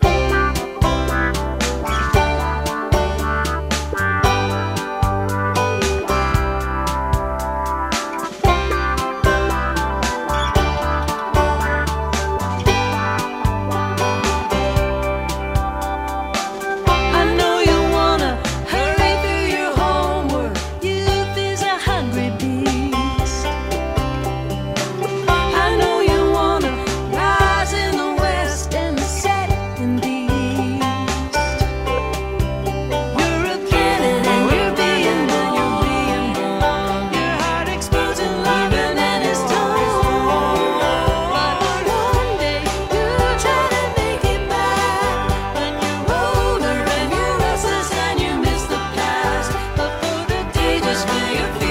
(captured from the webstream)